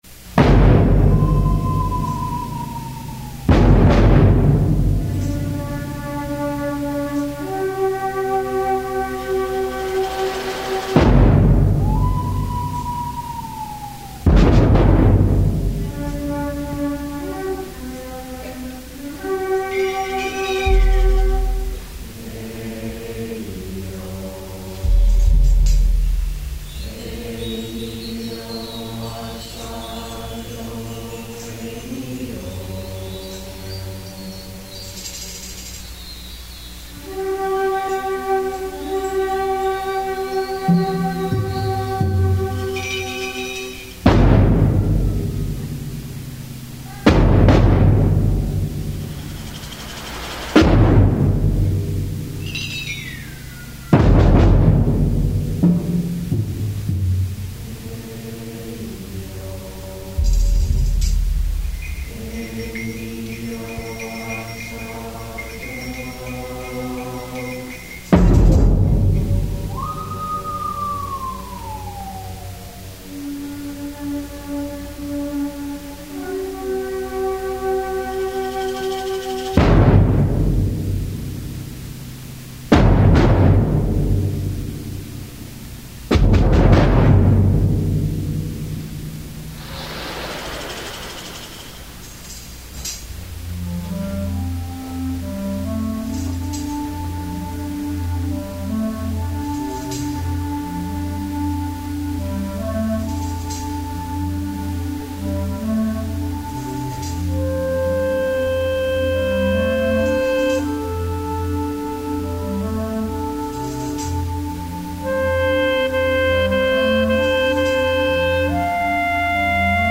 Gattung: Konzertwerk
Besetzung: Blasorchester